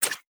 Tab Select 9.wav